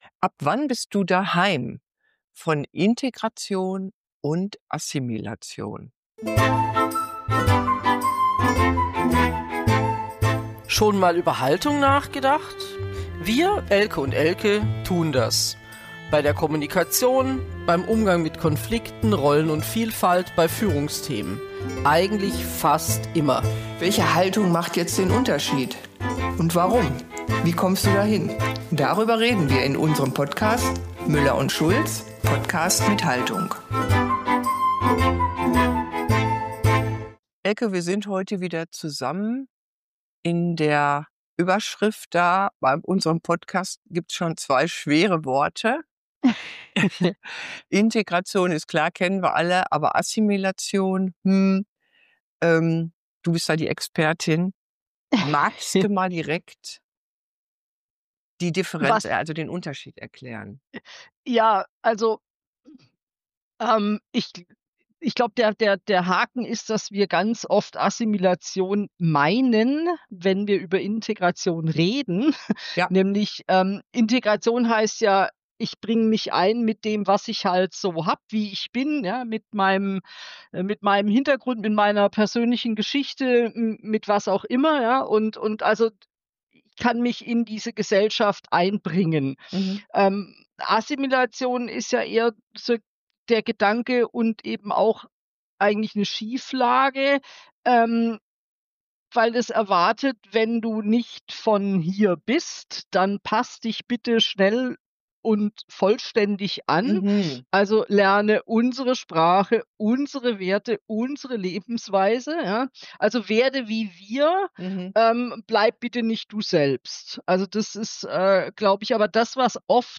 Mal gehen sie vom Dialekt aus, mal von der Hautfarbe oder einem Tattoo. Ein Gespräch darüber, warum Zugehörigkeit nie erzwungen werden darf, wie schnell unser Gehirn „schubladisiert“ und warum Vielfalt uns menschlich weiterbringt. Wie immer wird offen, reflektiert und wohltuend ehrlich diskutiert.